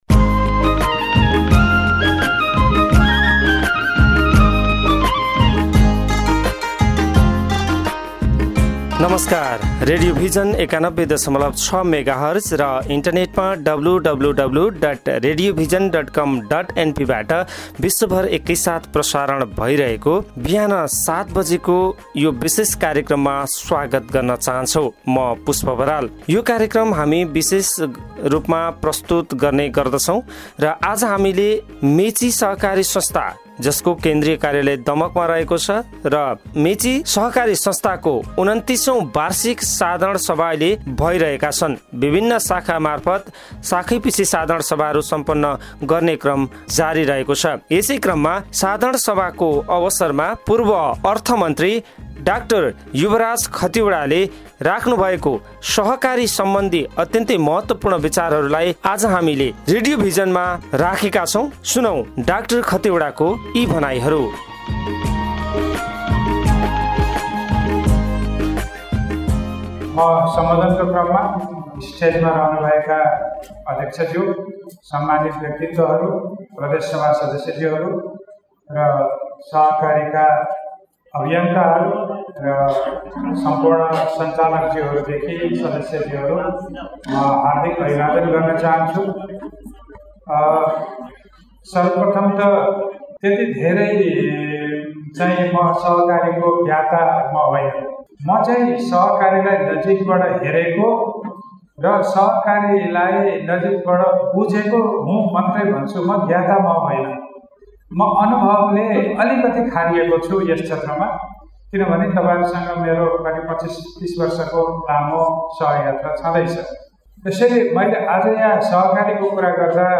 मेची बहुमुउद्देश्यीय सहकारी संस्थाको २९औ बार्षिक साधारण सभालाई संबोधन गर्नु हुदै पुर्व अर्थ मन्त्री डा. युवराज खतिवडा ( उर्लाबारीमा आयोजित २९औ बार्षिक साधारण सभा )